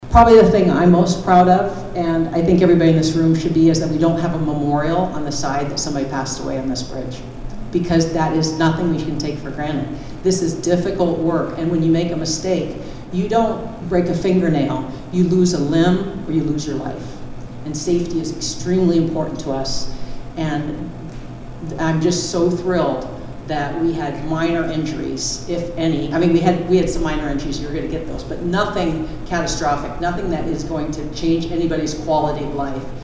Pierre Mayor Steve Harding and Fort Pierre Mayor Gloria Hanson also spoke during the ceremony.